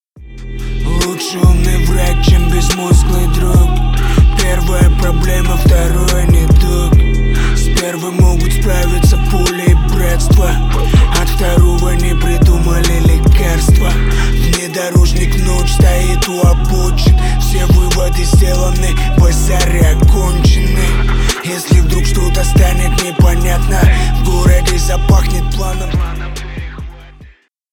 • Качество: 320 kbps, Stereo
Рэп и Хип Хоп